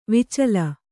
♪ vicala